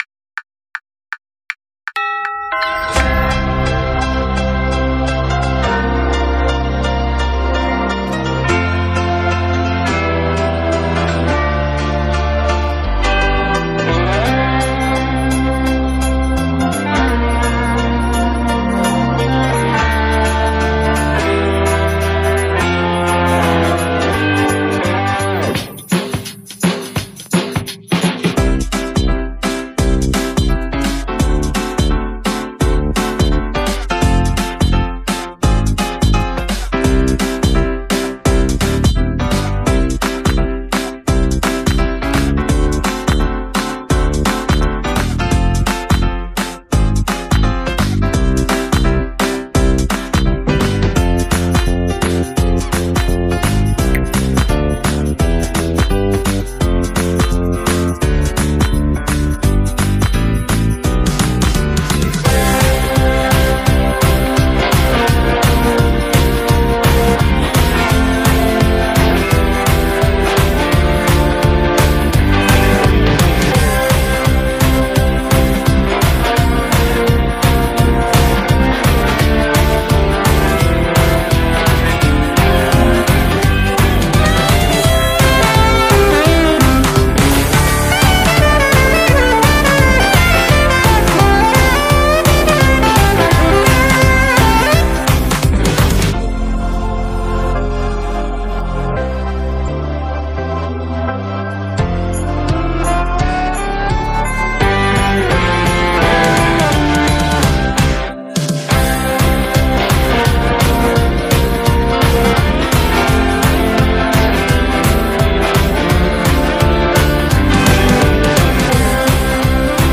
Nadales Ed. Infantil 2025
6e-Que-be-que-ja-es-Nadal-base-claqueta.mp3